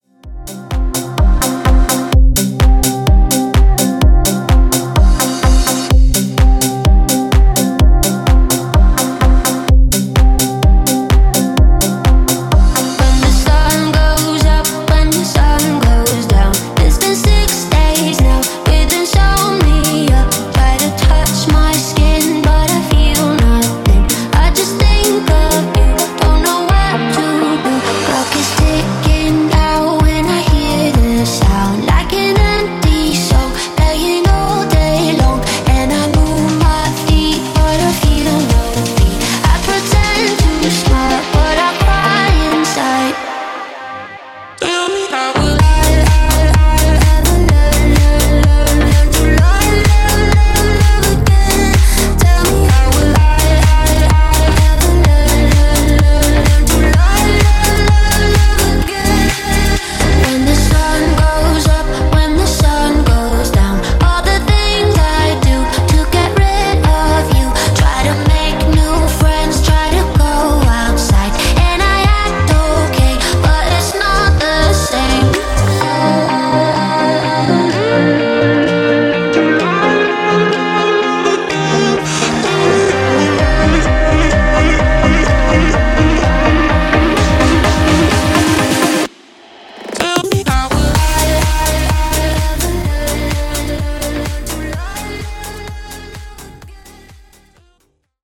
Hybrid Bigroom Edit)Date Added